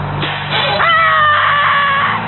PERSON-Yell+1
Tags: combat